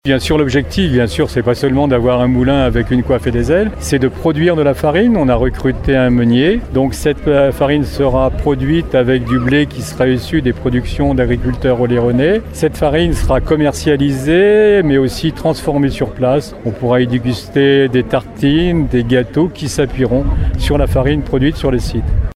L’objectif de ces travaux est de pouvoir remettre le moulin en service, après plus d’un siècle d’inactivité, afin de relancer la production de farine oléronaise, comme le souligne Michel Parent :